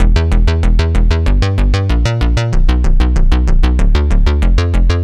AM_OB-Bass_95-E.wav